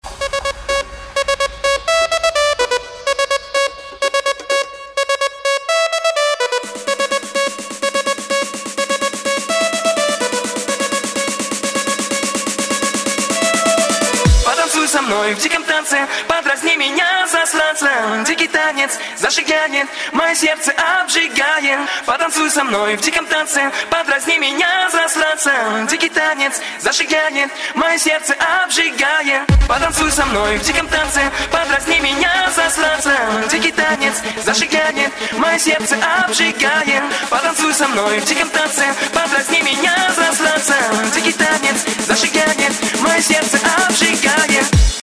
• Качество: 128, Stereo
веселые
dance
mix